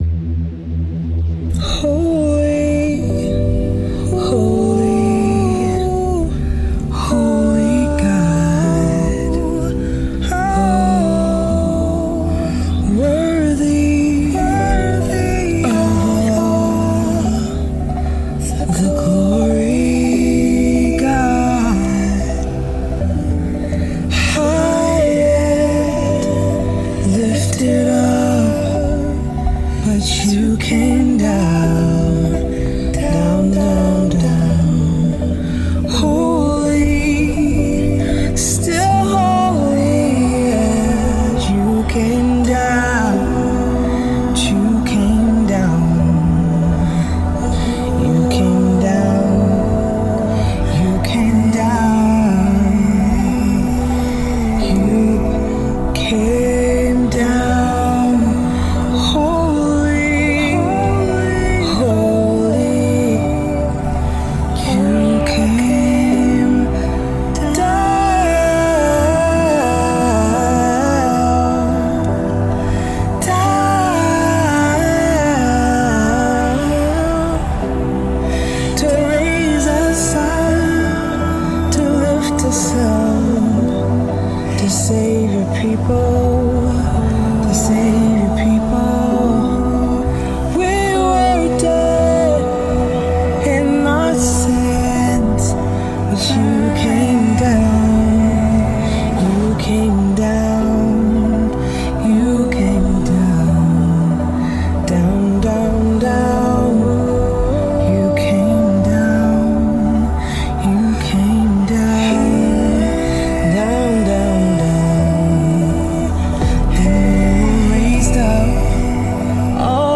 improvised worship
spontaneous worship